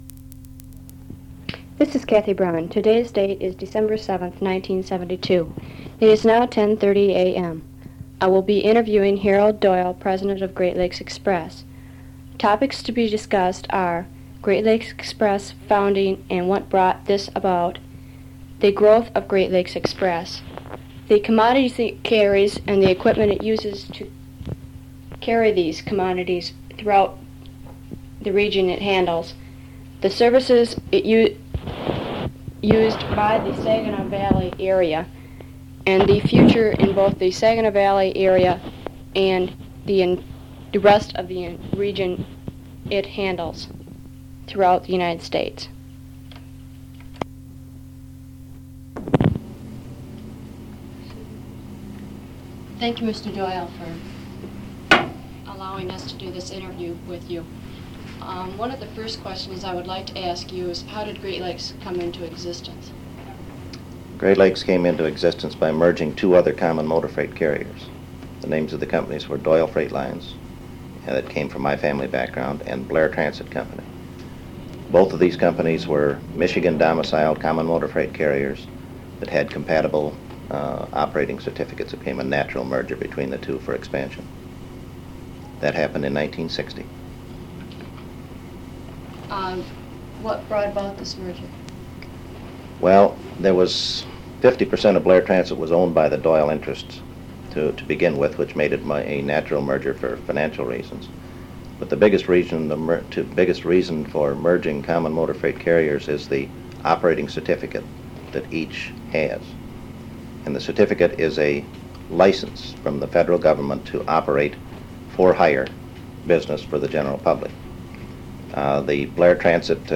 Oral History
Original Format Audiocassette